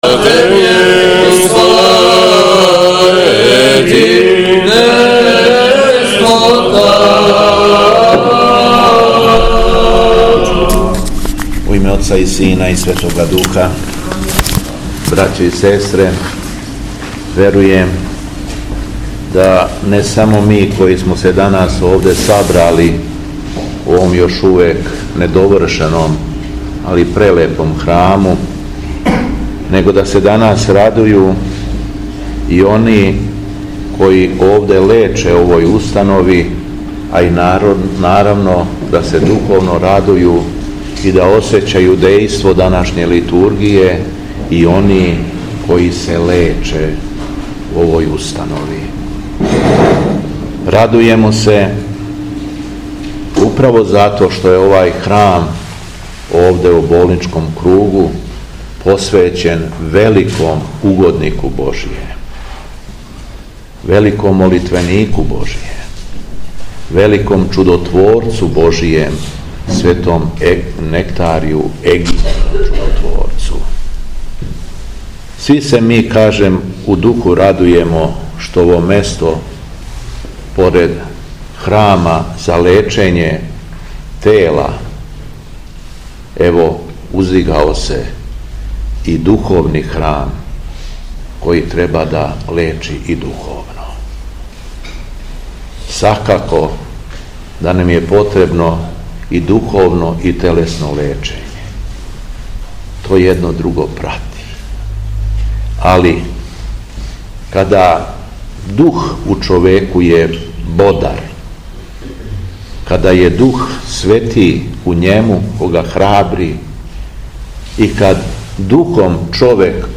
Беседа Његовог Високопреосвештенства Митрополита шумадијског г. Јована
Након прочитане перикопе из Светог Јеванђеља, Високопросвећени владика обратио се сабраном верном народу богонадахнутом беседом: